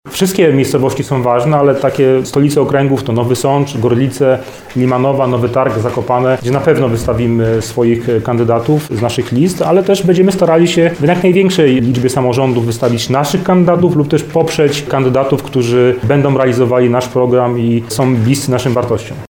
Będziemy też starali się w jak największej liczbie samorządów wystawić naszych kandydatów lub też poprzeć kandydatów, którzy będą realizowali nasz program i są bliscy naszym wartościom – mówi Arkadiusz Mularczyk, wiceprzewodniczący zarządu wojewódzkiego PiS w Małopolsce.